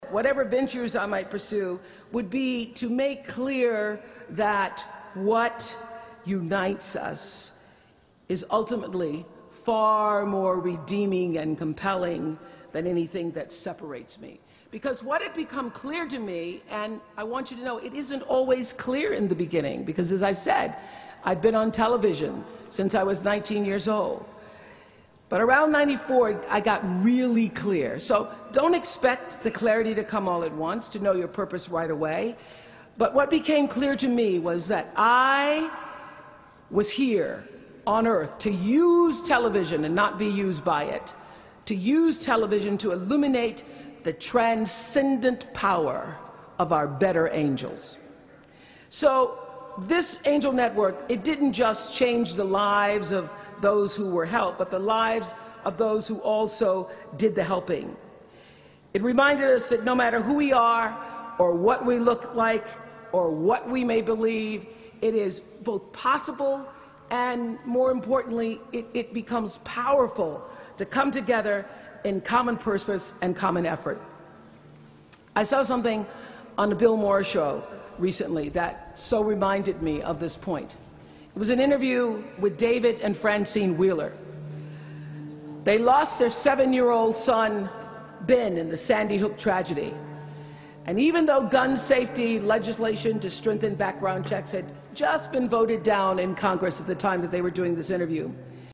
公众人物毕业演讲第360期:奥普拉2013在哈佛大学(9) 听力文件下载—在线英语听力室